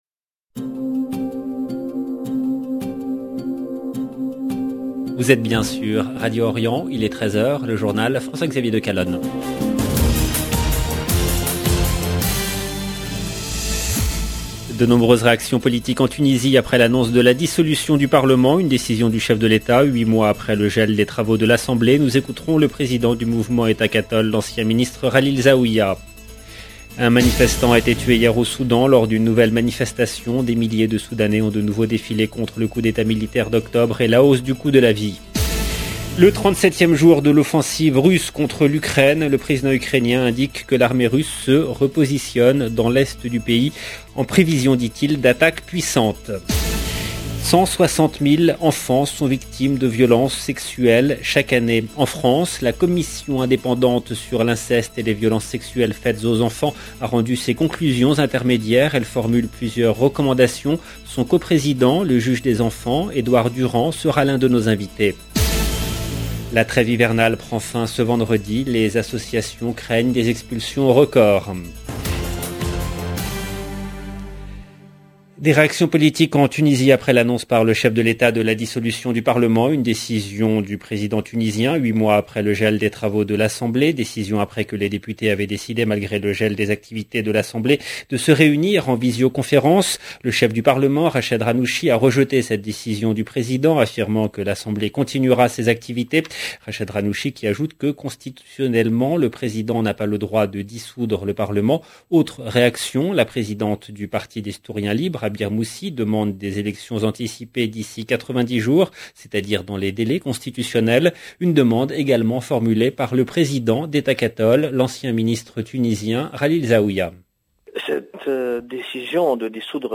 Son co président, le juge des enfants Edouard Durand sera l’un de nos invités.